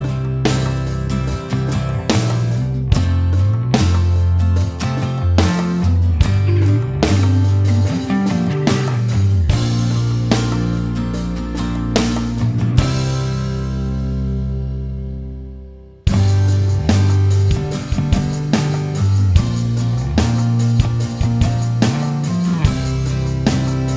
Minus Lead Guitar Rock 3:58 Buy £1.50